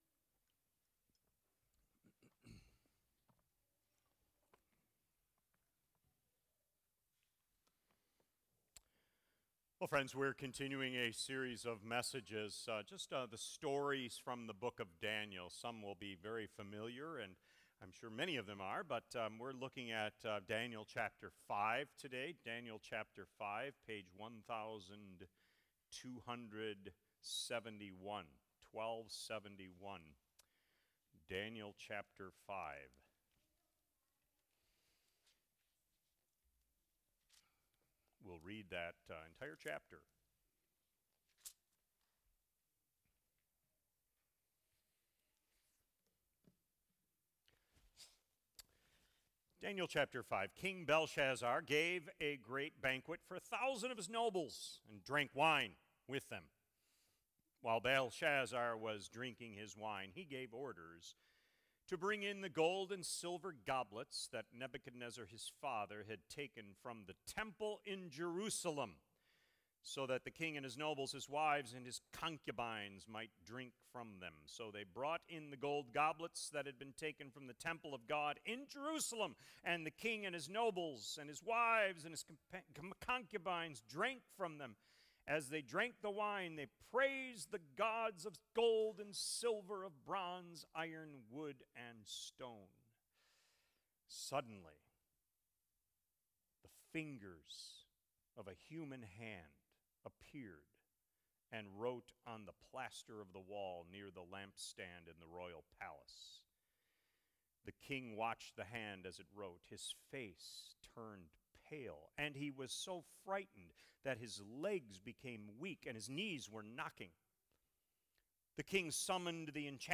Sermons | Faith Community Christian Reformed Church